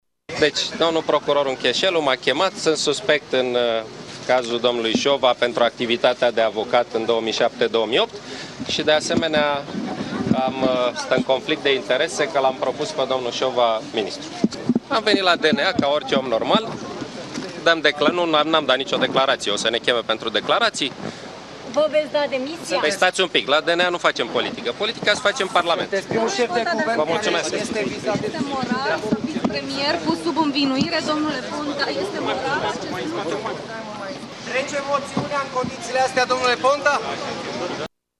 La ieșire, premierul a declarat că este suspect în dosarul lui Dan Şova.
ponta-declaratii.mp3